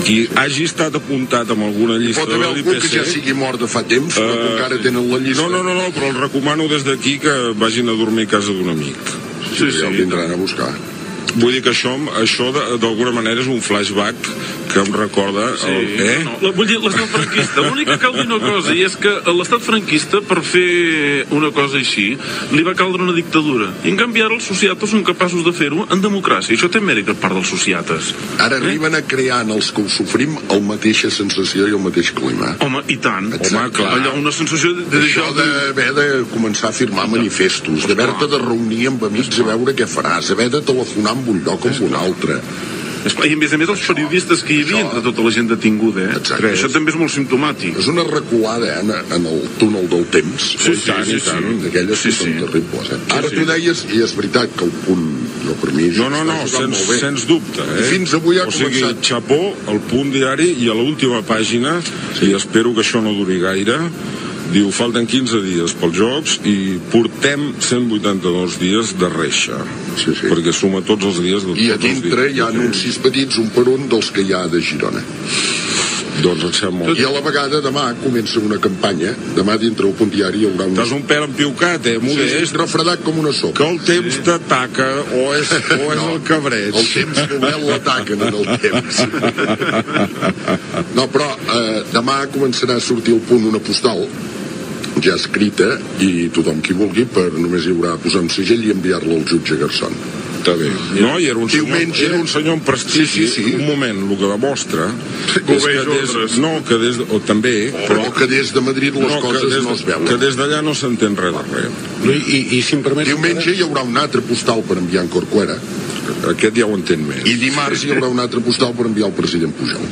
Presentador/a
FM